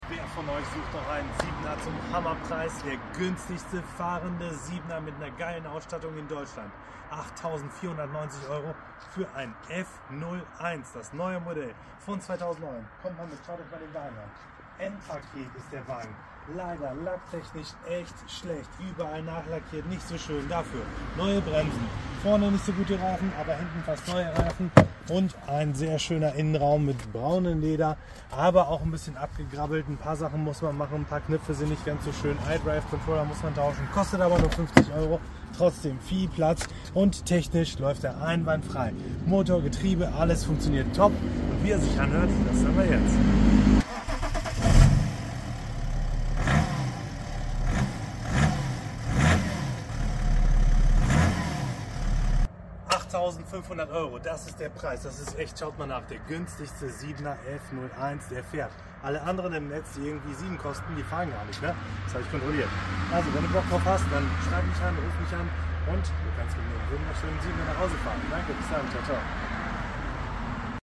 Günstiger "fahrender" BMW 730d F01 sound effects free download